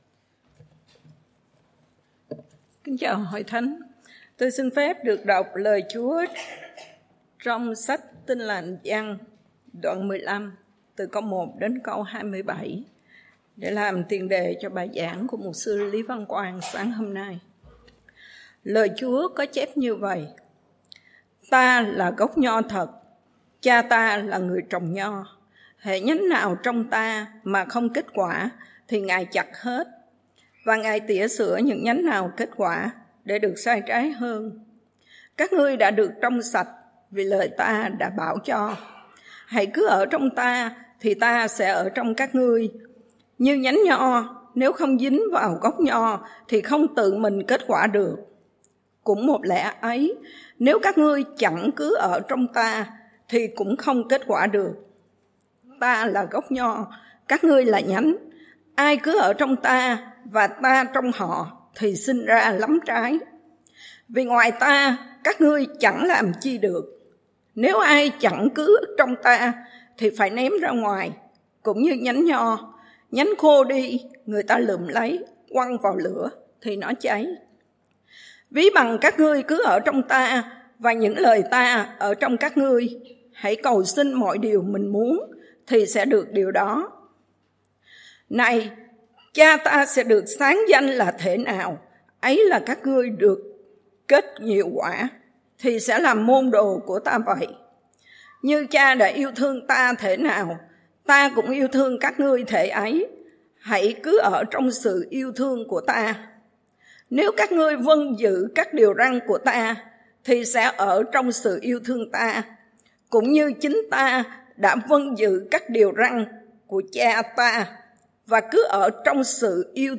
Bài Giảng